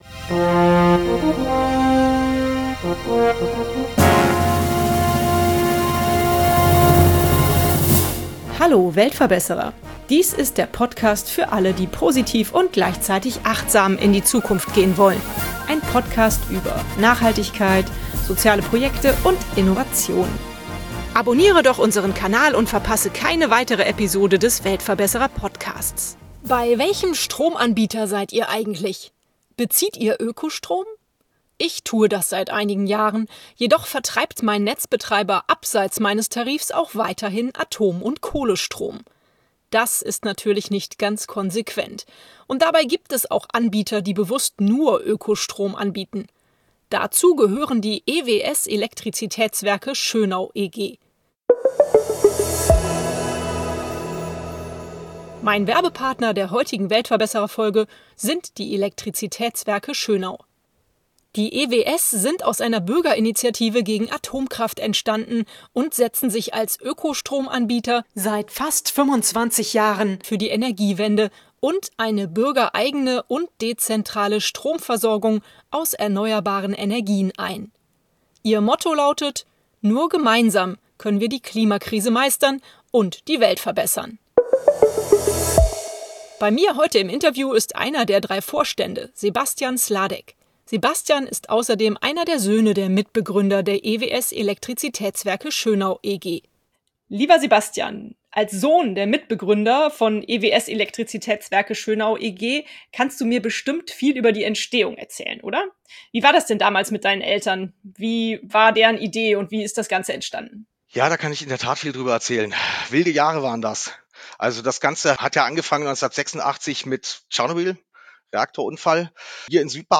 ***DAUERWERBESENDUNG*** Mehr